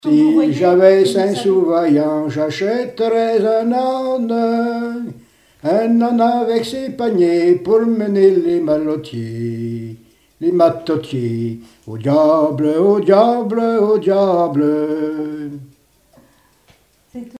Divertissements d'adultes - Couplets à danser
chansons et témoignages parlés
Pièce musicale inédite